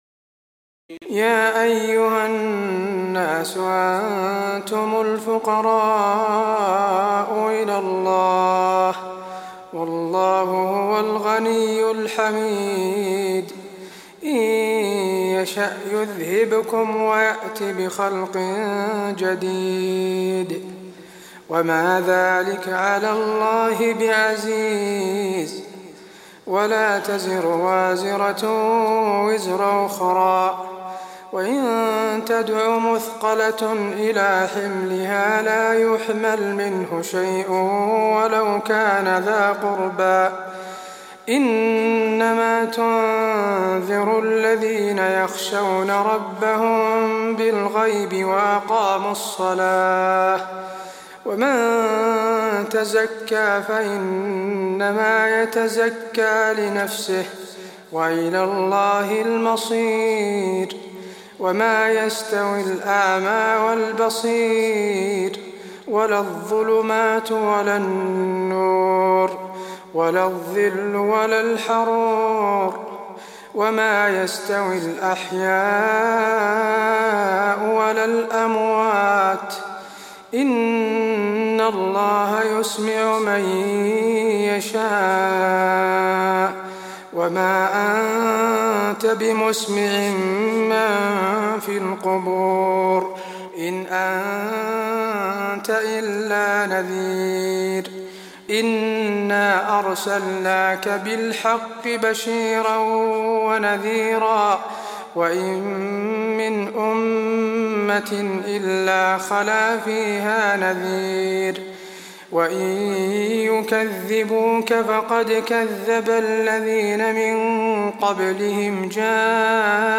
تراويح ليلة 21 رمضان 1423هـ من سور فاطر (15-45) و يس(1-12) Taraweeh 21 st night Ramadan 1423H from Surah Faatir and Yaseen > تراويح الحرم النبوي عام 1423 🕌 > التراويح - تلاوات الحرمين